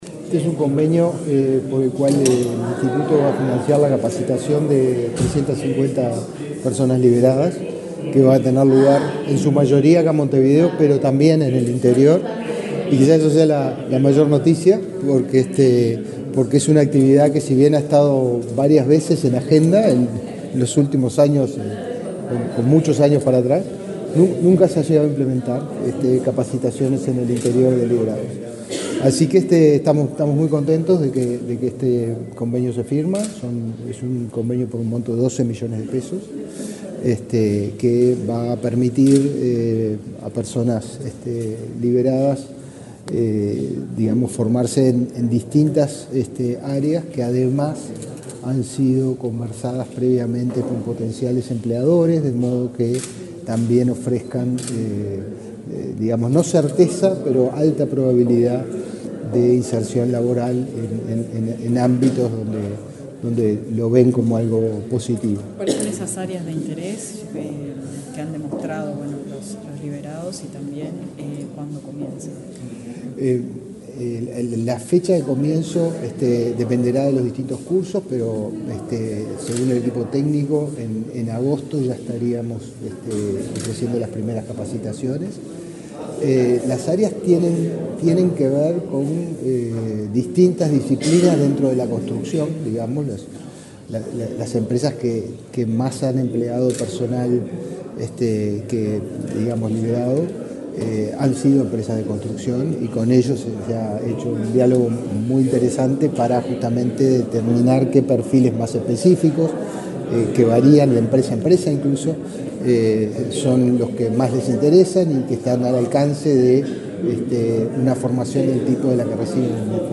Declaraciones del director general del Inefop, Pablo Darscht
El Ministerio de Desarrollo Social, a través de la Dirección Nacional de Apoyo al Liberado, firmó un convenio con el Instituto Nacional de Empleo y Formación Profesional (Inefop), para proveer cursos y capacitaciones en oficios a personas que recuperaron su libertad. Antes, el director general del Inefop, Pablo Darscht, dialogó con la prensa sobre las características del acuerdo.